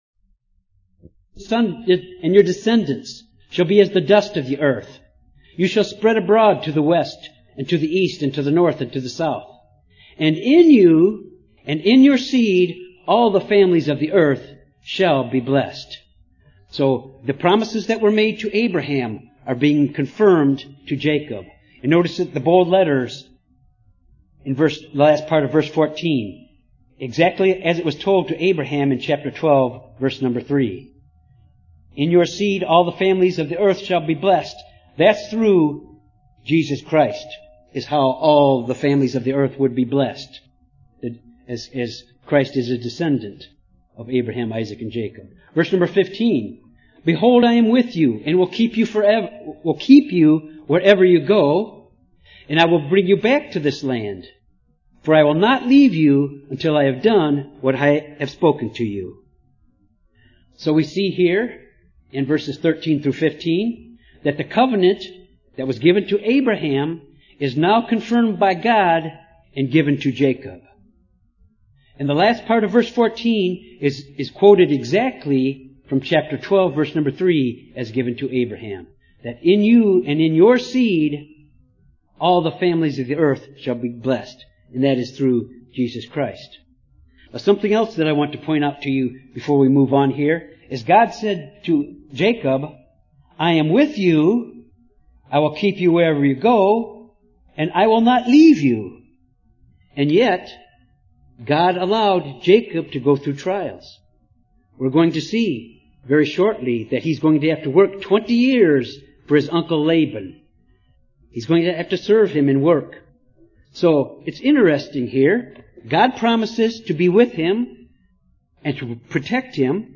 Due to technical difficulties, the first 10 minutes are missing. This Bible study covers Genesis 28-30. Jacob travels to Paddan-aram to obtain a wife where he meets Rachel, the daughter of his uncle Laban.
UCG Sermon Studying the bible?